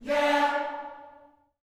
YEAH D 4B.wav